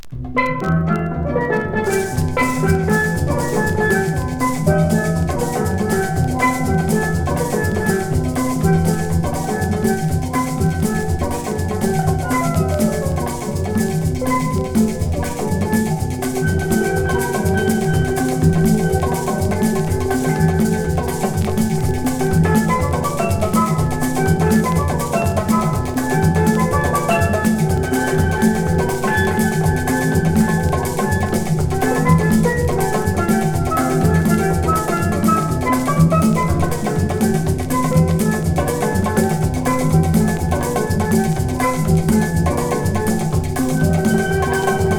スティール・パンという楽器の魅力が溢れ、心地良い音が響く爽快な1枚。